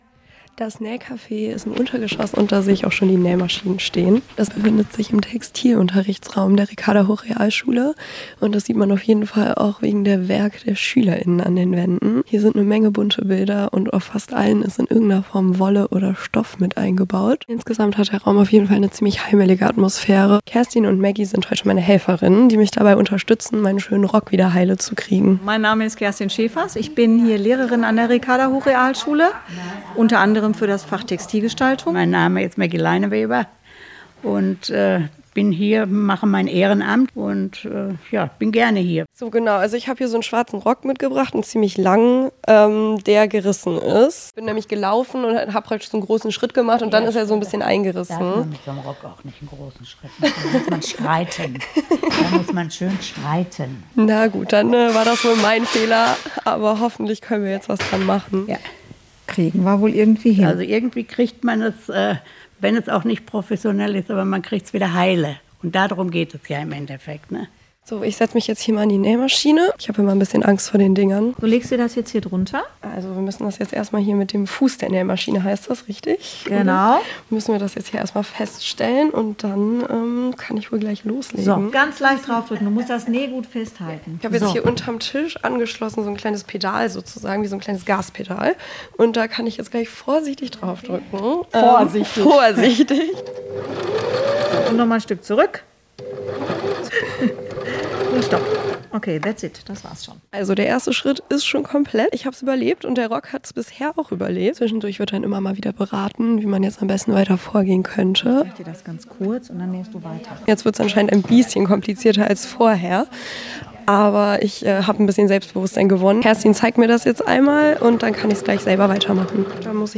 Serie: Reportage